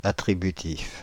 Ääntäminen
Ääntäminen Paris: IPA: [a.tʁi.by.tif] France (Île-de-France): IPA: [a.tʁi.by.tif] Haettu sana löytyi näillä lähdekielillä: ranska Käännöksiä ei löytynyt valitulle kohdekielelle.